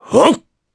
Kaulah-Vox_Jump_jp.wav